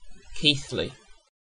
Keighley (/ˈkθli/
KEETH-lee[3][4]) is a market town and a civil parish[5] in the City of Bradford Borough of West Yorkshire, England.
En-uk-Keighley.ogg.mp3